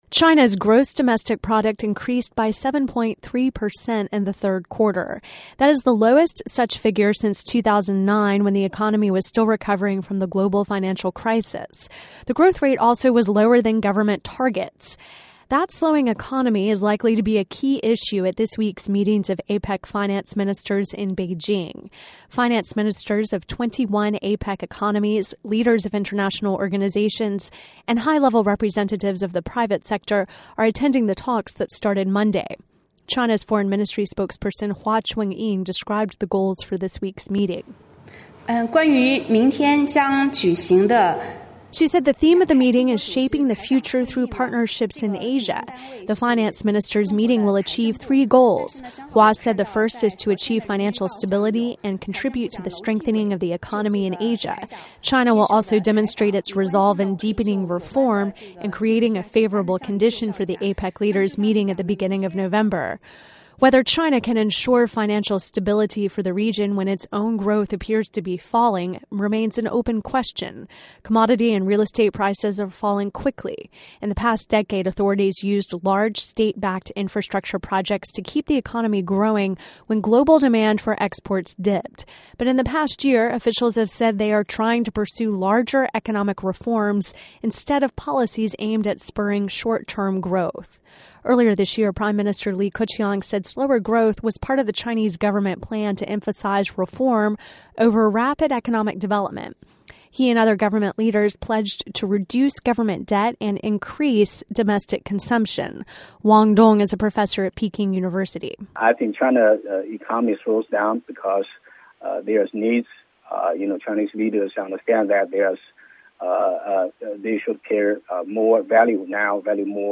China Economy - Report